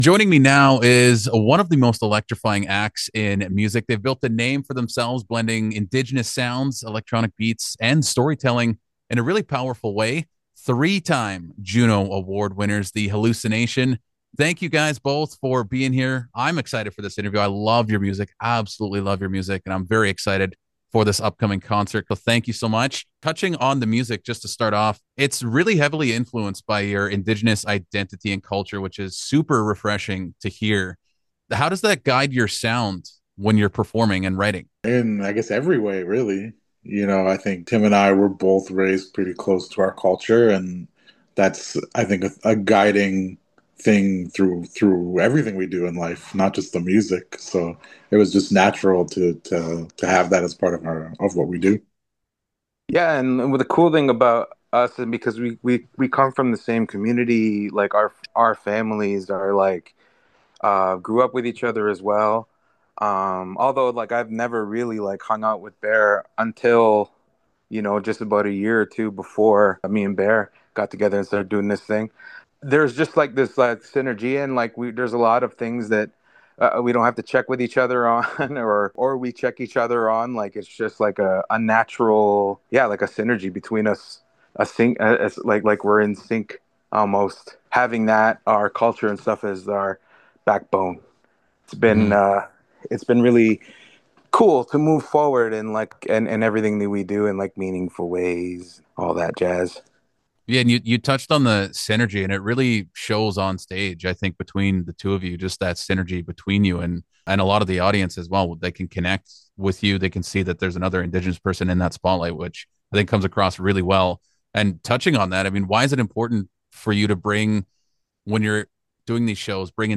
halluci-nation-full-interview.mp3